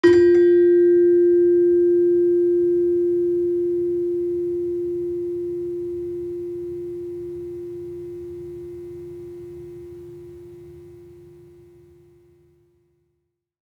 Gamelan Sound Bank
Gender-3-F3-f.wav